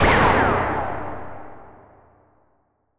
boompow.wav